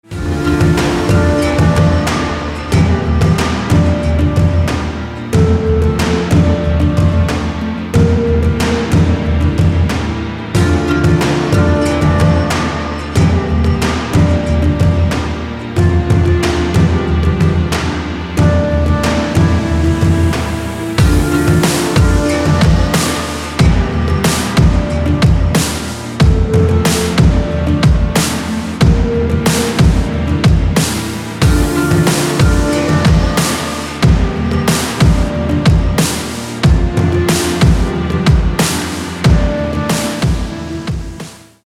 • Качество: 192, Stereo
мелодия